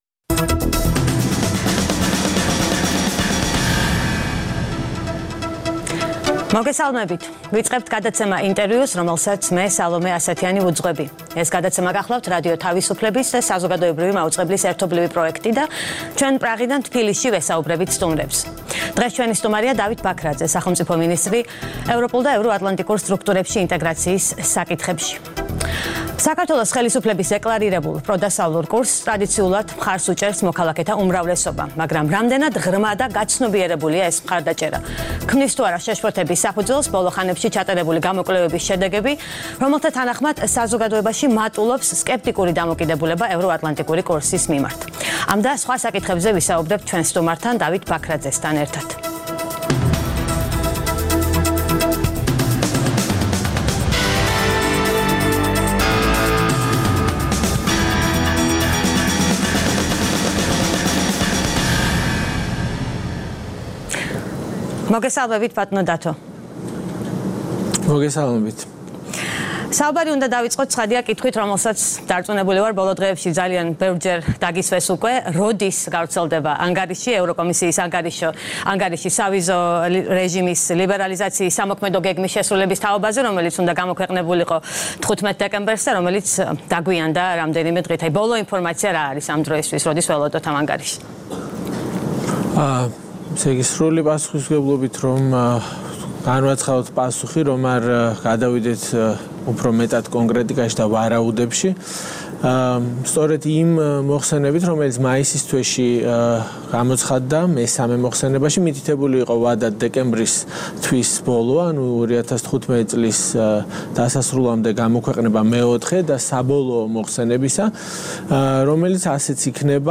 ყოველკვირეული გადაცემა „ინტერview“ არის რადიო თავისუფლებისა და საზოგადოებრივი მაუწყებლის ერთობლივი პროექტი. მასში მონაწილეობისთვის ვიწვევთ ყველას, ვინც გავლენას ახდენს საქართველოს პოლიტიკურ პროცესებზე. „ინტერview“ არის პრაღა-თბილისის ტელეხიდი
რადიო თავისუფლების პრაღის სტუდიიდან